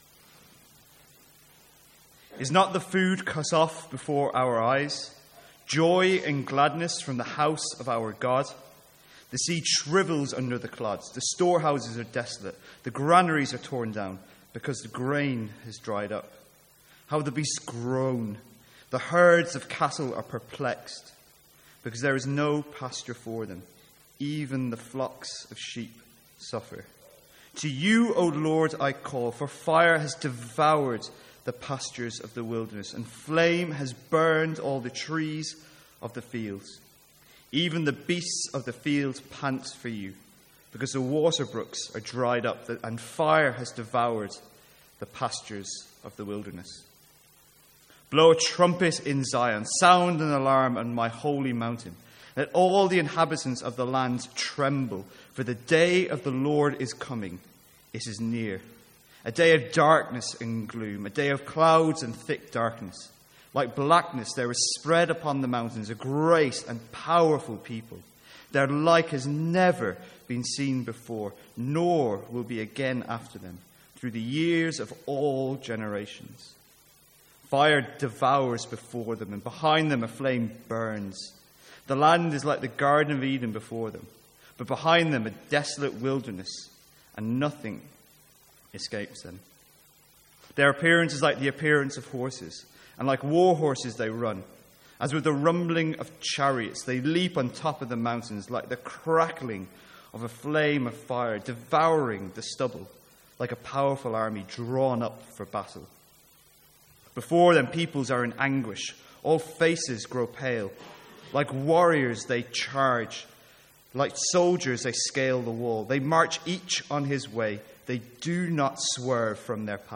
Sermons | St Andrews Free Church
From the Sunday evening series in Joel.